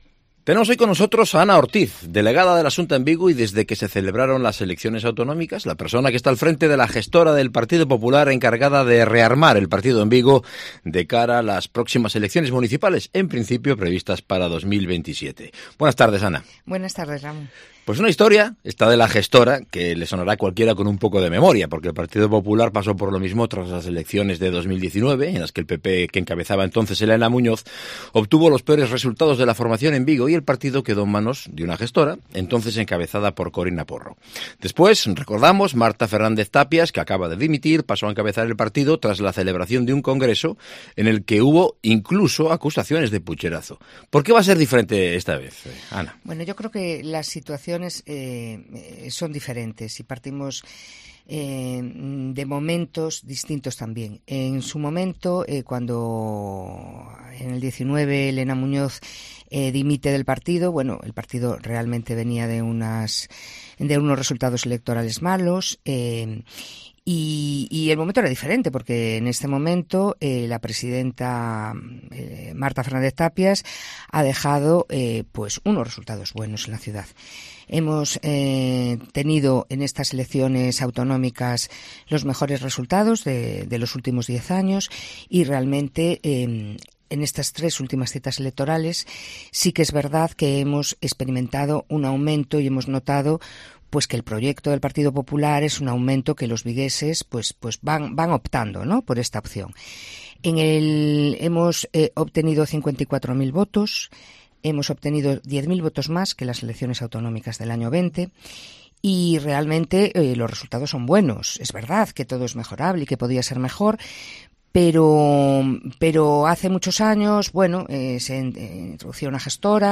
Entrevista con Ana Ortiz, delegada de la Xunta en Vigo